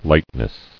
[light·ness]